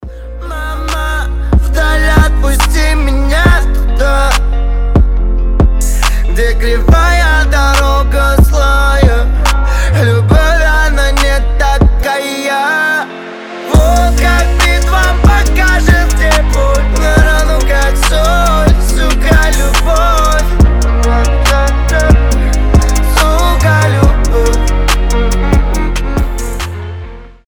• Качество: 320, Stereo
лирика
душевные
грустные